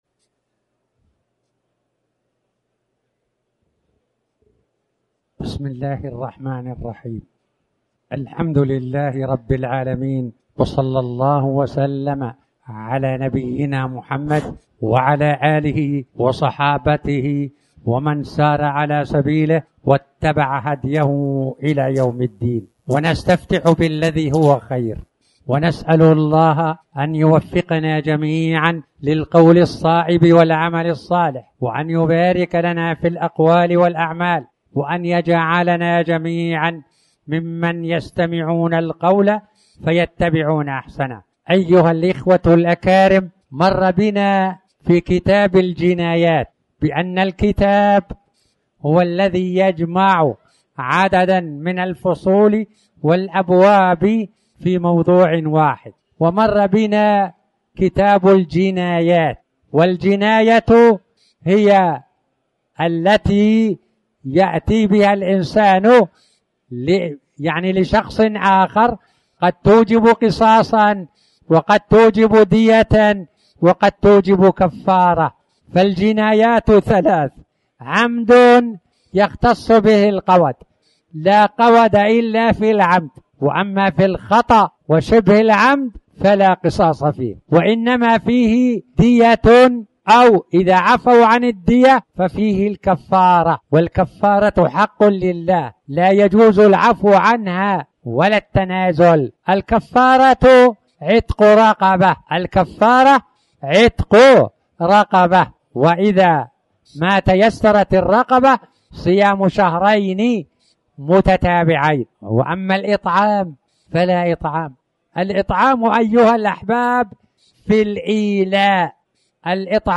تاريخ النشر ٨ شعبان ١٤٣٩ هـ المكان: المسجد الحرام الشيخ